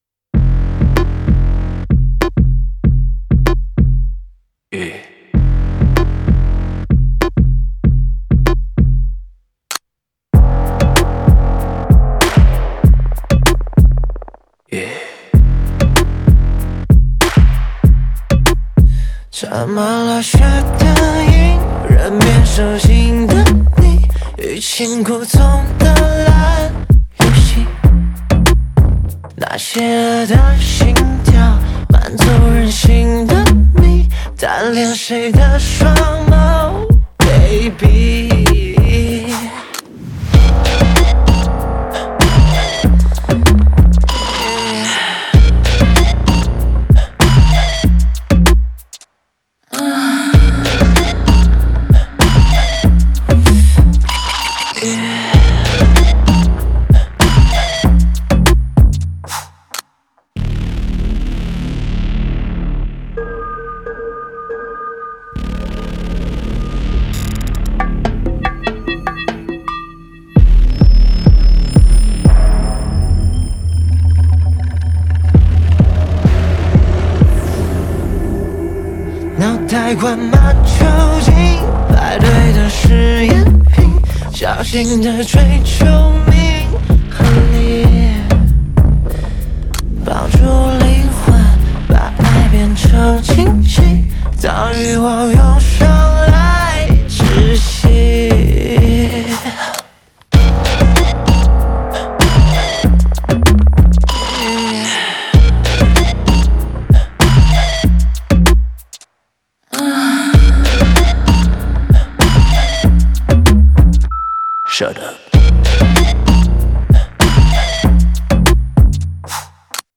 Ps：在线试听为压缩音质节选，体验无损音质请下载完整版 https